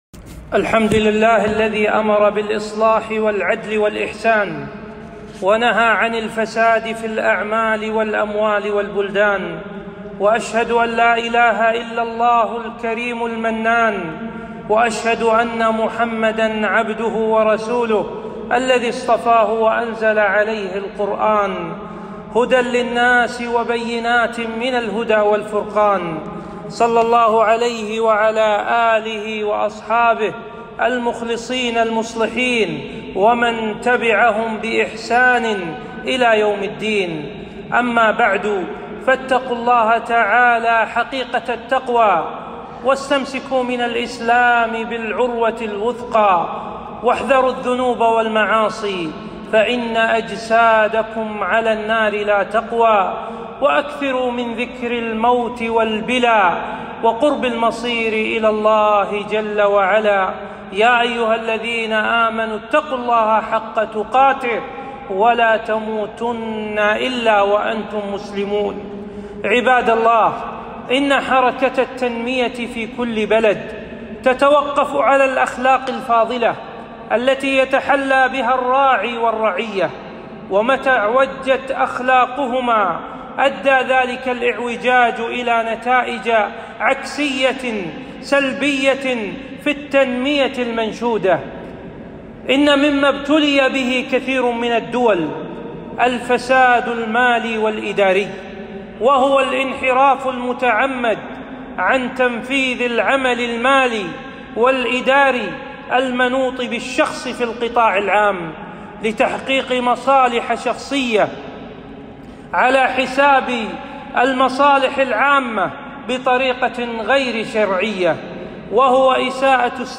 خطبة - خطورة الفساد المالي والإداري على المجتمع